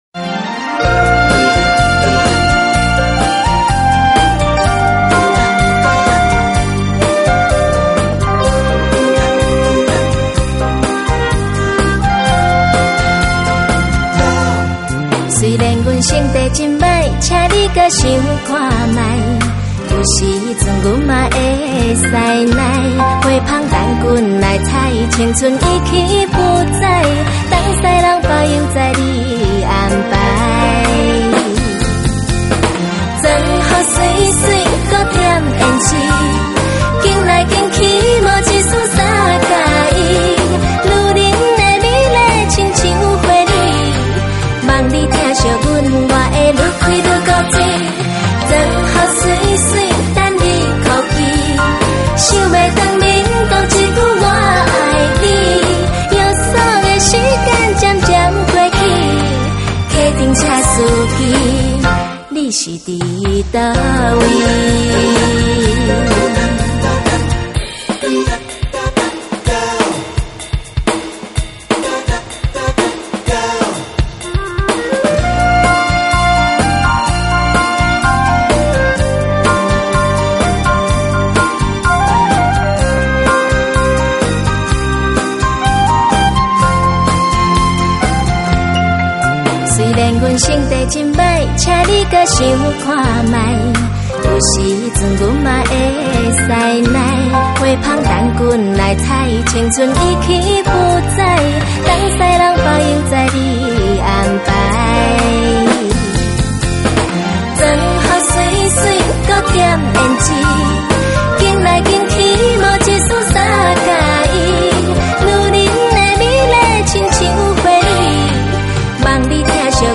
於是整張專輯的音樂曲風，清快活潑佔了大多數。